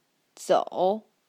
Let’s investigate how well speech recognition does, provided that the input is standardised Mandarin and clearly enunciated by a native teacher.
t1-zou3.mp3